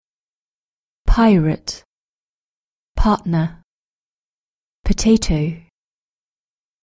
british-english-british-accent-glottal-stop-1.mp3